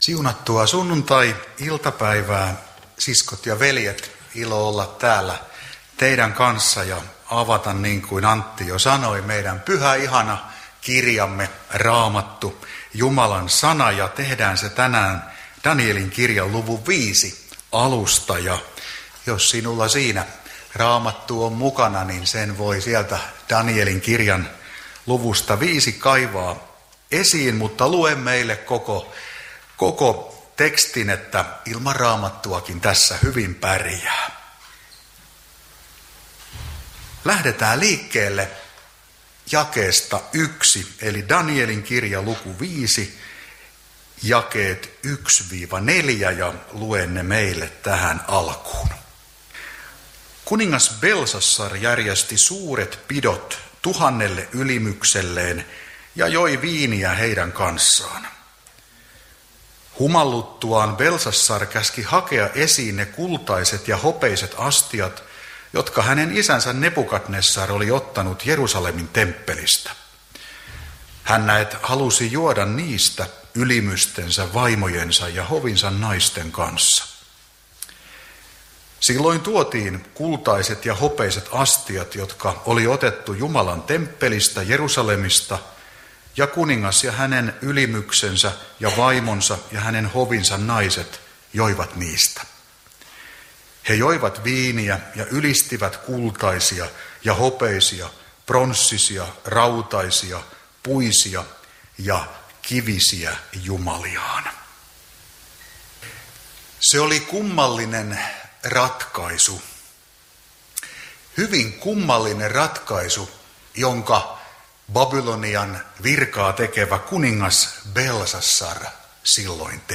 opetus